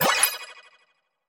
Звуки чекпоинта
На этой странице собраны звуки чекпоинтов — от четких электронных сигналов до игровых оповещений.
Звук для озвучки чекпоинта в игре